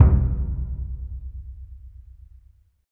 Index of /90_sSampleCDs/Roland LCDP14 Africa VOL-2/PRC_Burundi Drms/PRC_Burundi Drms